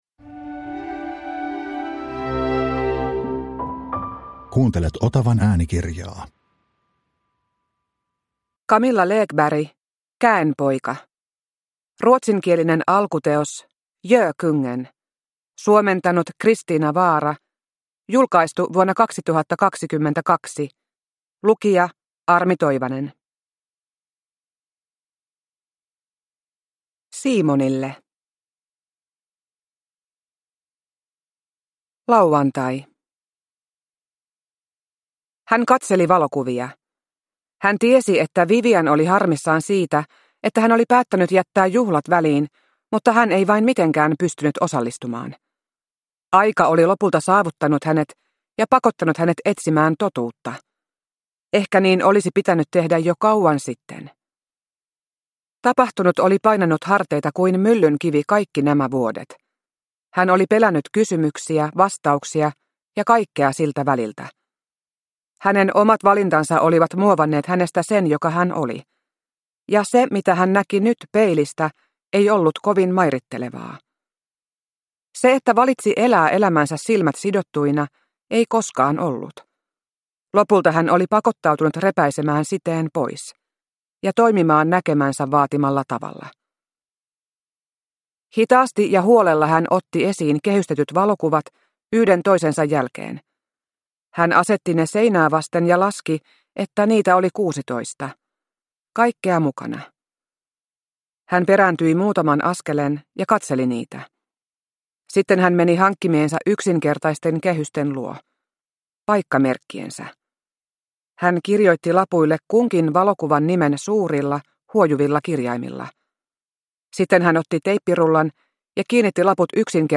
Käenpoika – Ljudbok – Laddas ner